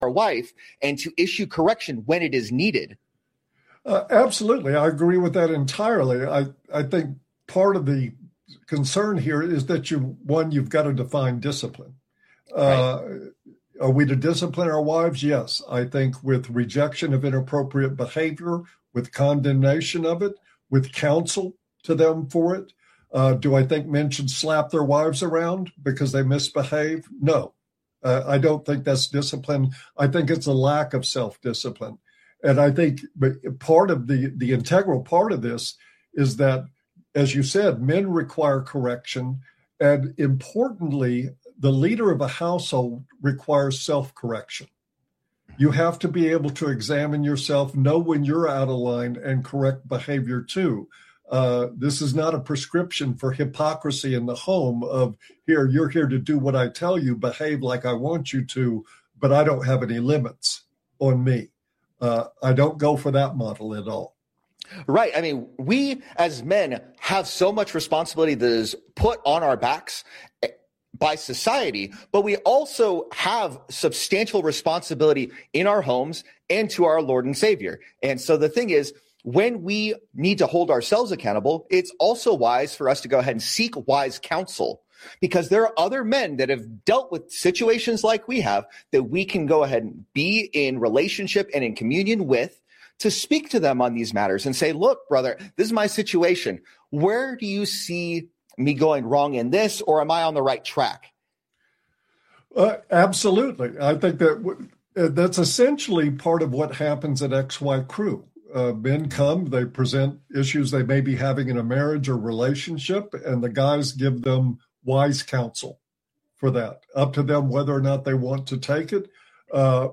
Cooling Transformers - a touch glitchy at the start